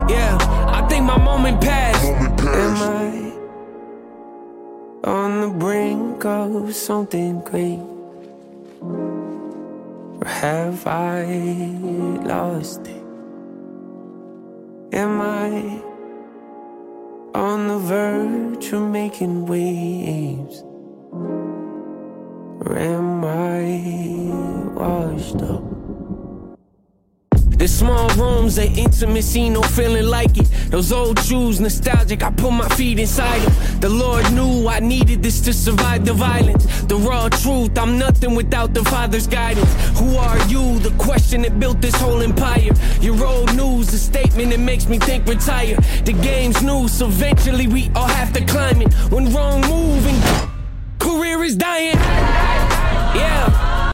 brand-new, melodious, and energetic emotional song